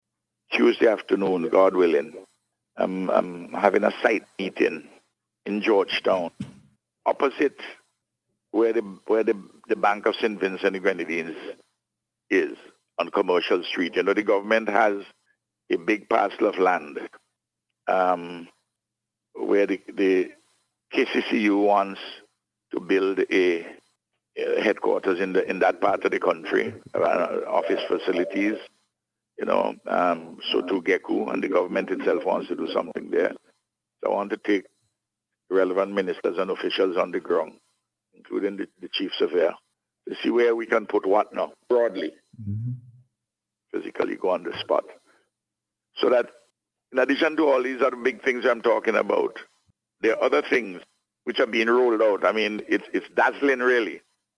He made the announcement, during the Issue At Hand programme on WE FM on Sunday.